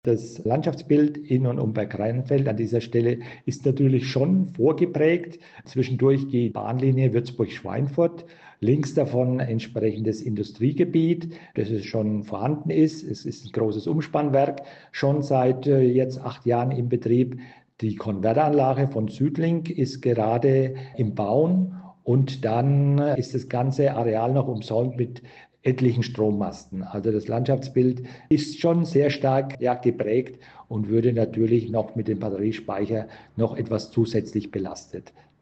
Wir sprechen mit Ulrich Werner, dem Bergrheinfelder Bürgermeister.